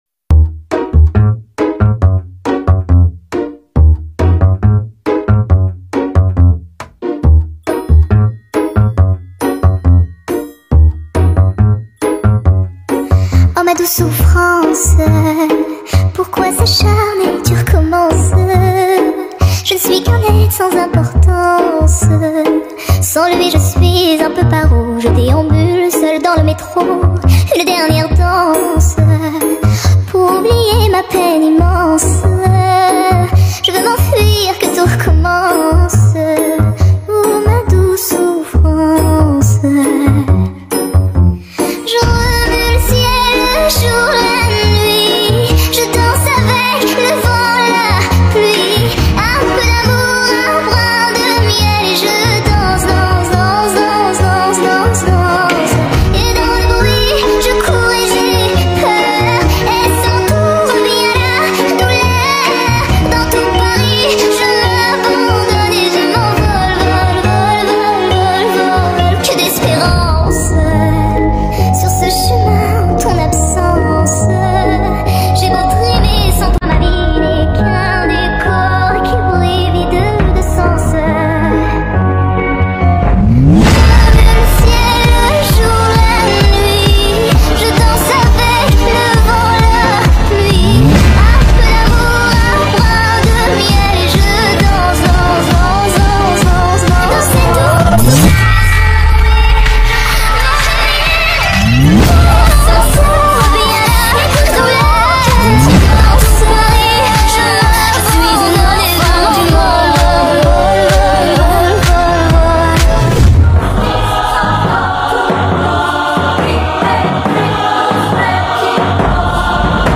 ورژن سریع شده و Sped Up